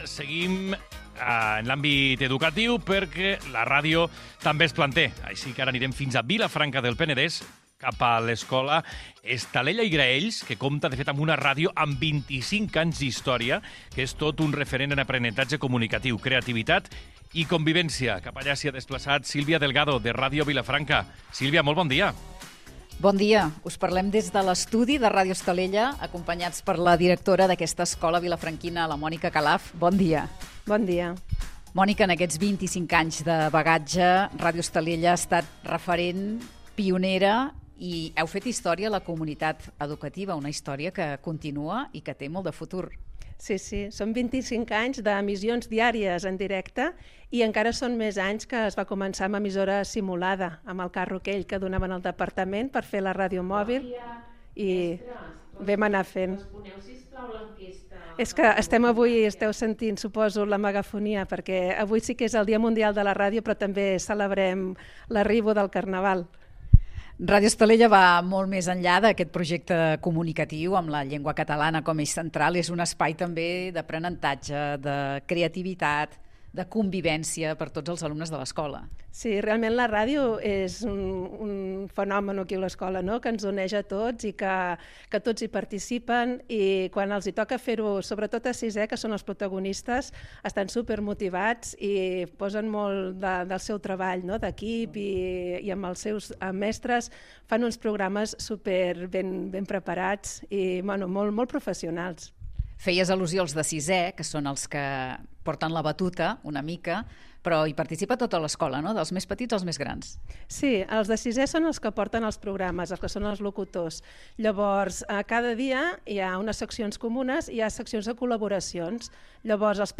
Entreteniment
FM
Fragment extret de l'arxiu sonor de La Xarxa.